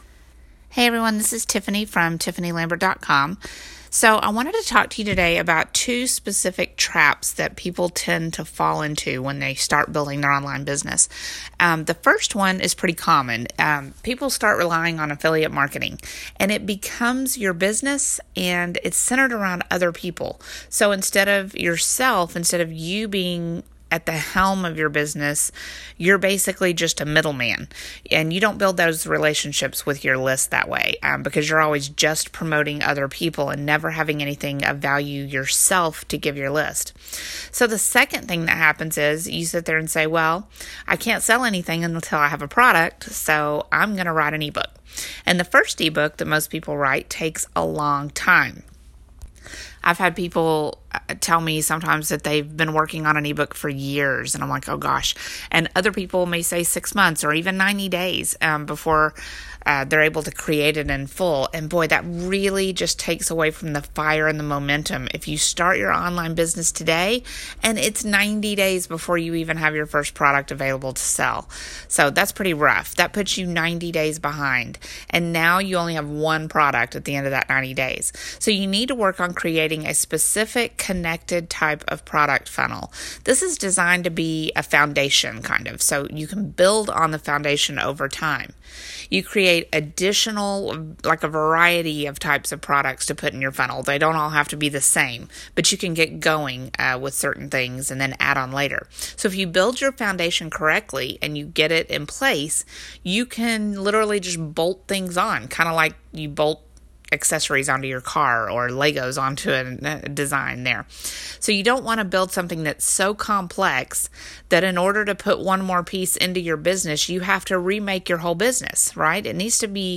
I opened one called How to Create Recorded Products Fast and I took out the first page and tweaked it for my own wording and recorded the audio of it for you.